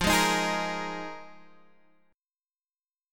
Fm11 chord